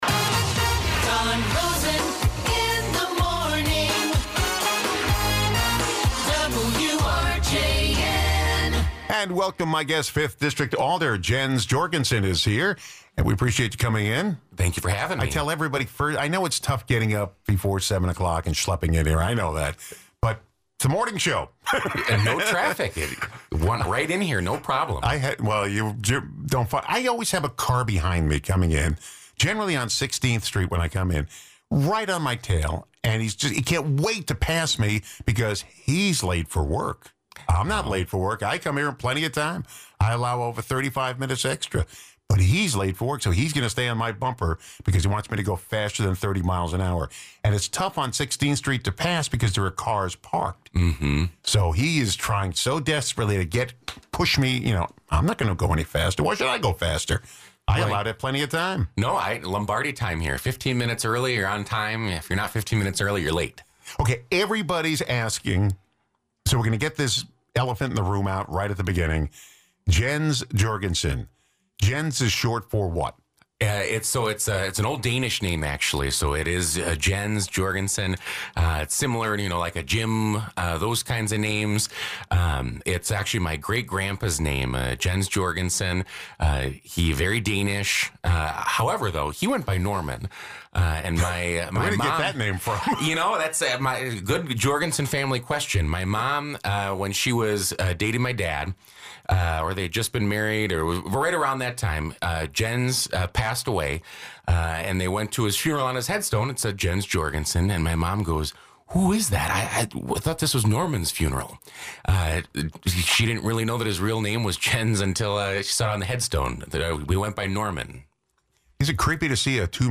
City of Racine Fifth District Alder, Jens Jorgensen, updates us on issues in his district and the City of Racine.